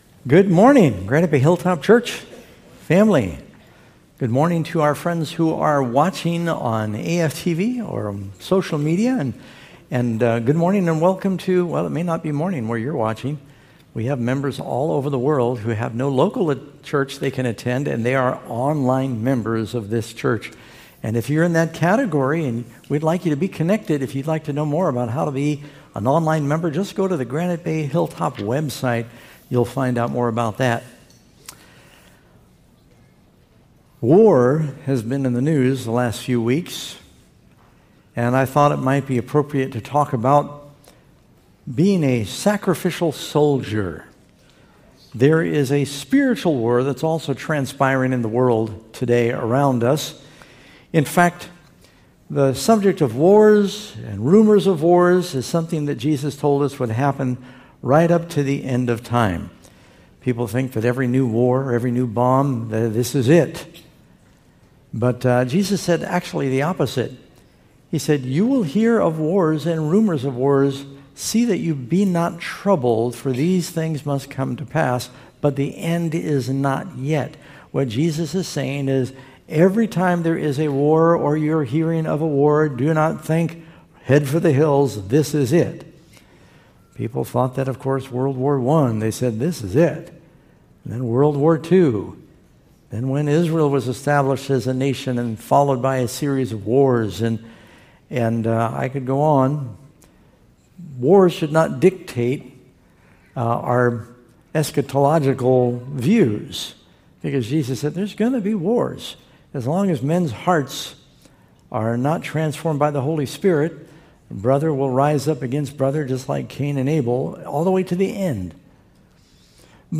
Come be inspired by a sermon that points to courage, purpose, and the cost of standing for what matters most in life.